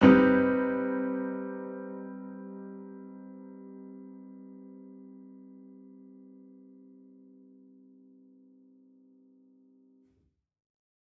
Index of /musicradar/gangster-sting-samples/Chord Hits/Piano
GS_PiChrd-Gsus4min6.wav